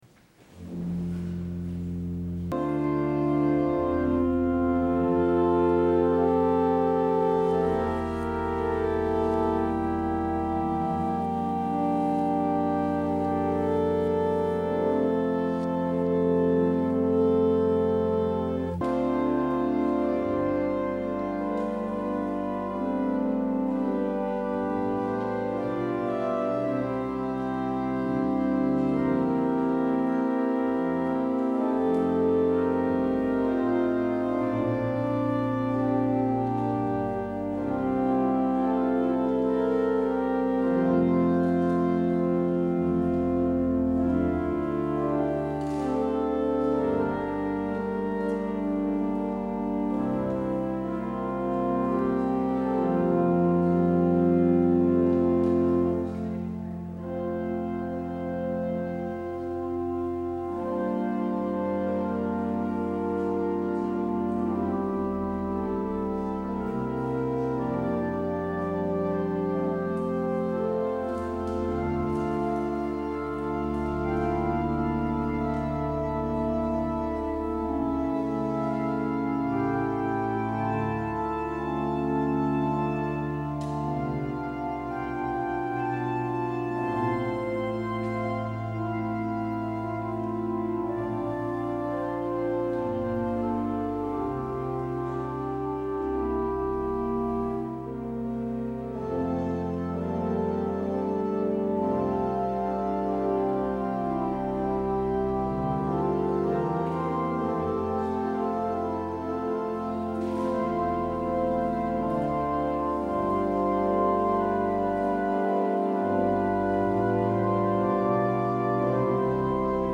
Op het orgel